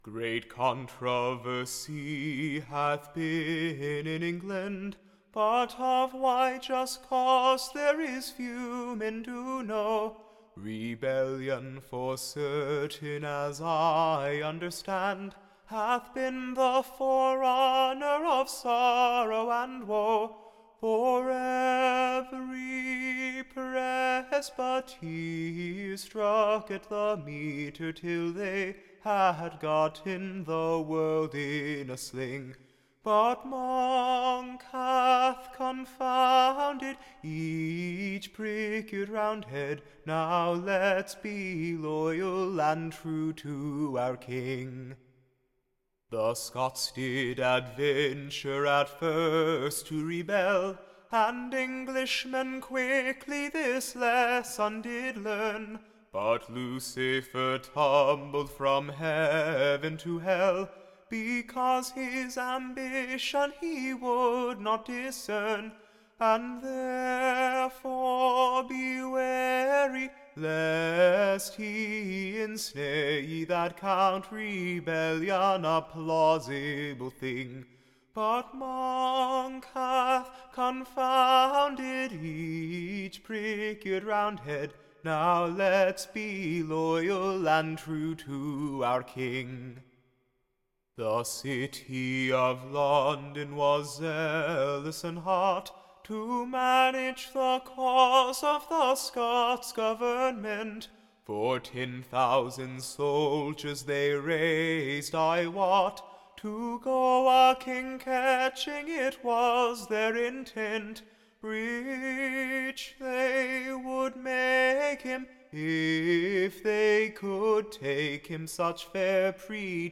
Recording Information Ballad Title A Loyal Subjects Admonition, or, a true Song of / Brittains Civil Wars.